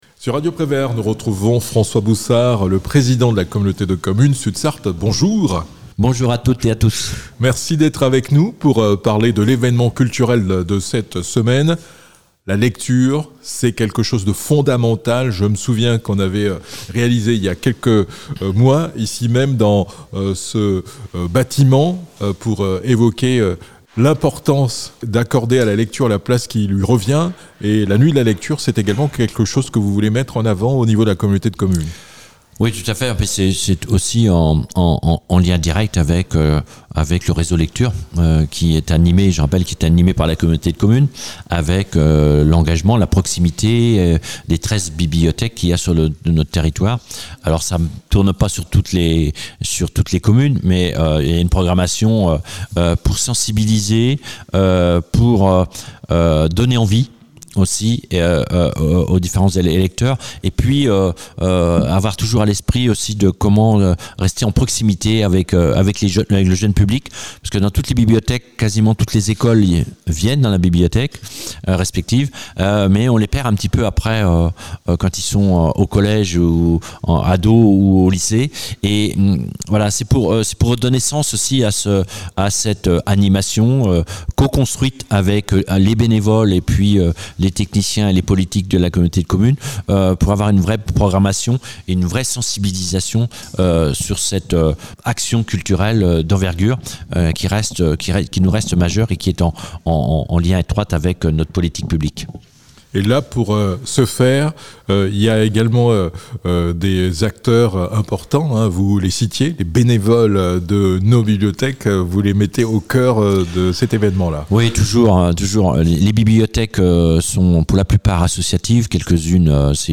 François Boussard, président de la Communauté de communes Sud Sarthe, revient sur l'importance de la lecture pour tous les publics et présente les différentes animations proposées.